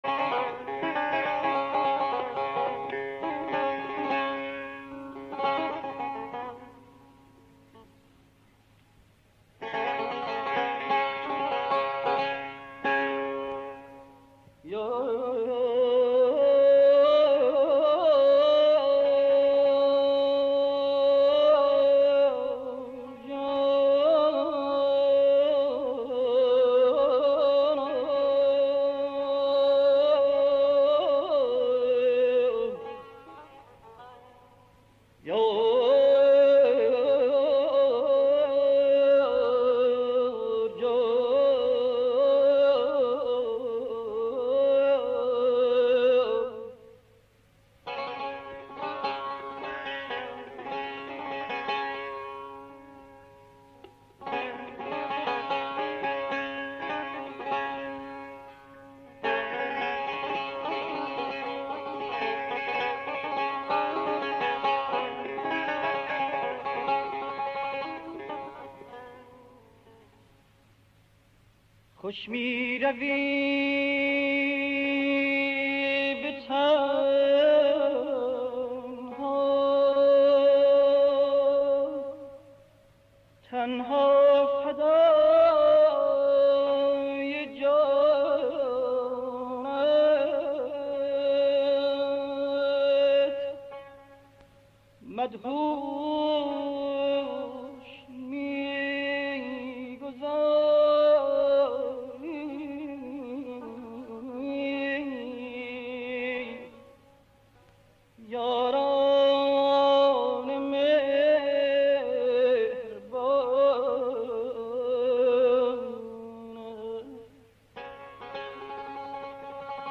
در مقام همایون